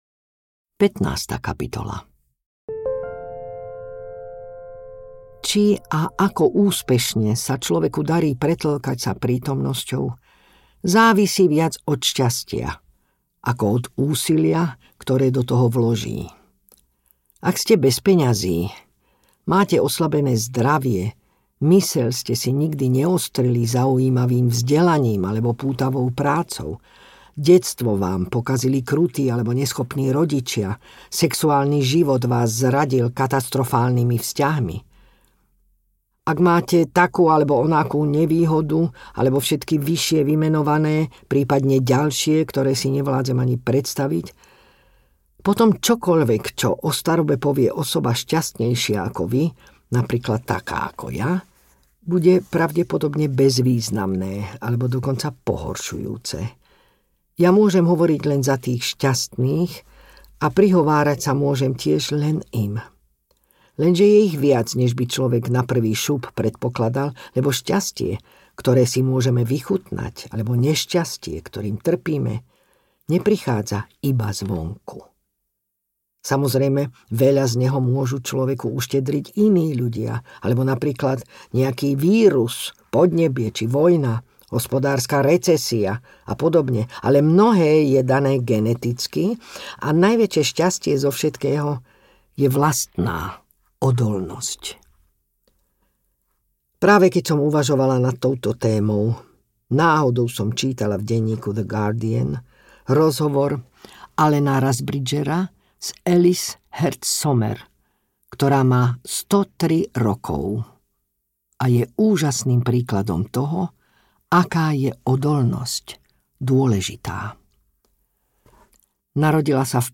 Niekde ku koncu audiokniha
Ukázka z knihy
• InterpretBožidara Turzonovová